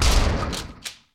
sounds_shotgun_fire_pump.ogg